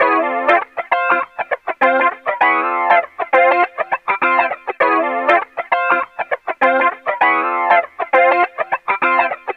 Loops guitares rythmique- 100bpm 3
Guitare rythmique 54